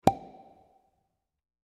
bubble.25f0bb62.mp3